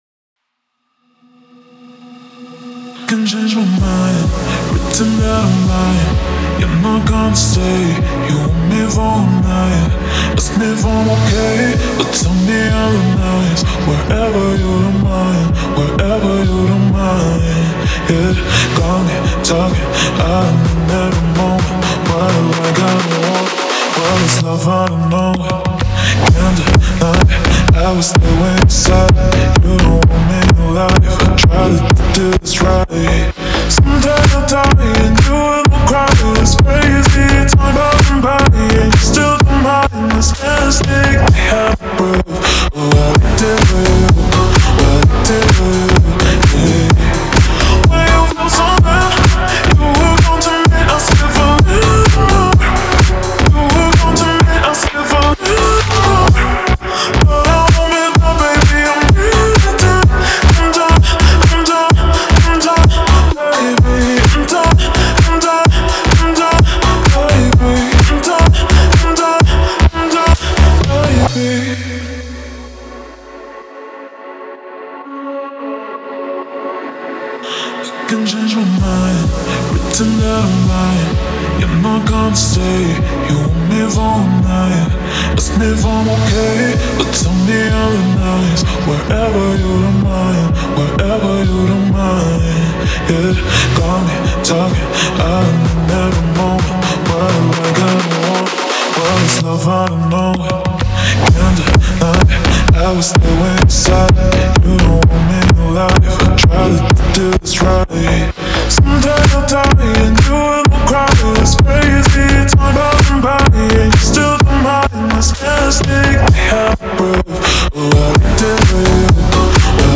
это трек в жанре блюз-рок